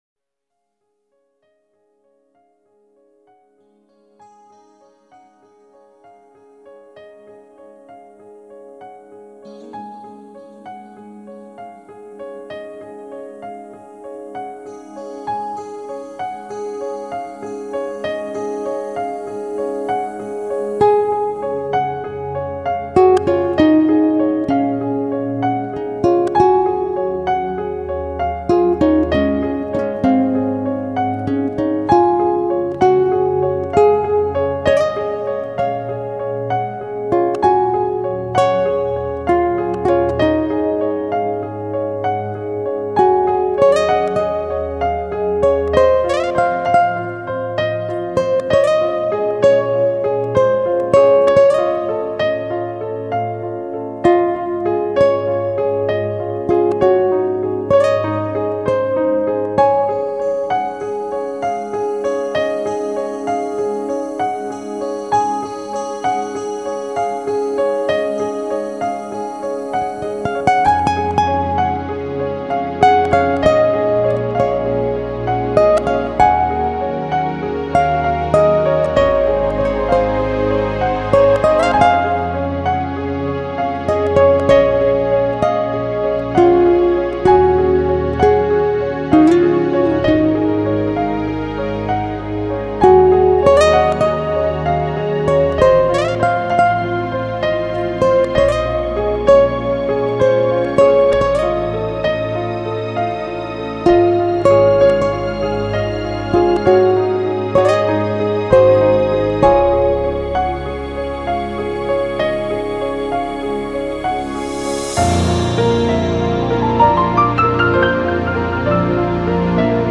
很喜欢这张精选，喜欢编者挑选曲目的精心安排，全然的舒服和放松，